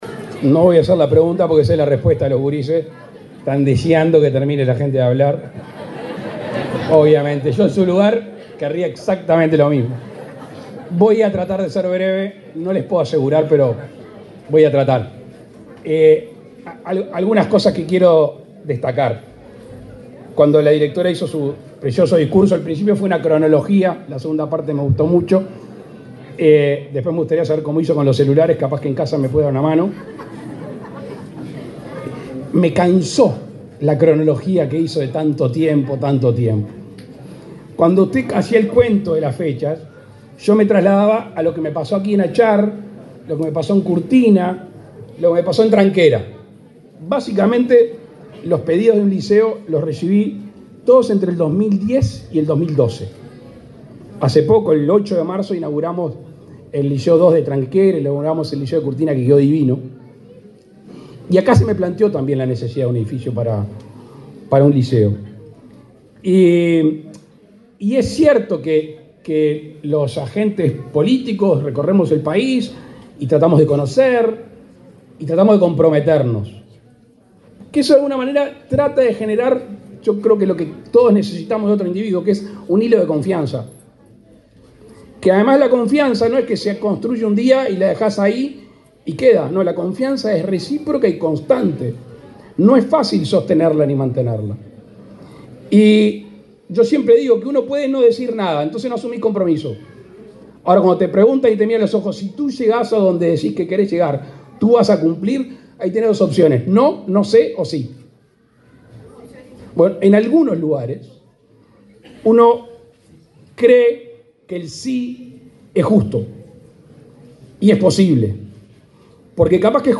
Palabras del presidente Luis Lacalle Pou
El presidente de la República, Luis Lacalle Pou, participó, este lunes 2, en la inauguración del edificio del liceo rural de la localidad de Achar, en